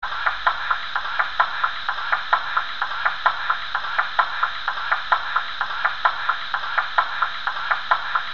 BR 50 aus 29820 Sound 2:
Sound2 : Doppelluftpumpe